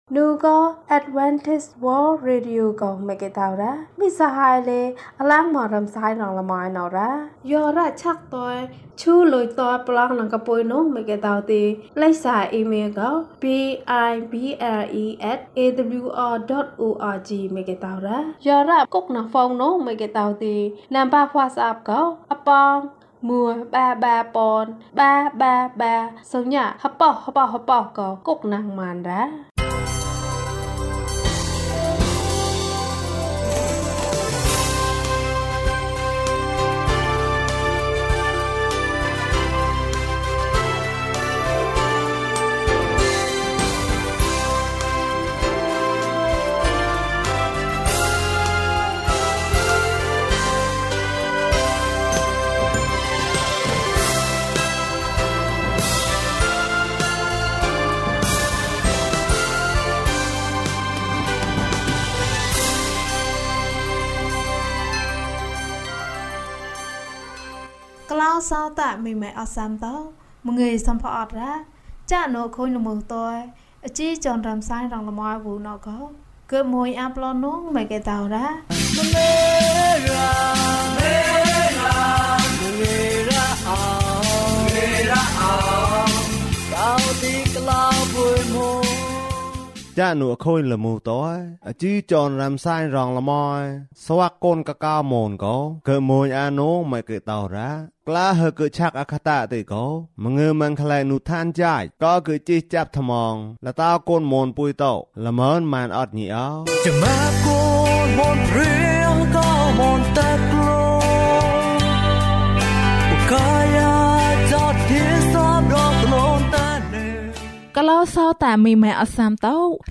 နံနက်ခင်း၌ တောက်ပခြင်း။၀၁ ကျန်းမာခြင်းအကြောင်းအရာ။ ဓမ္မသီချင်း။ တရားဒေသနာ။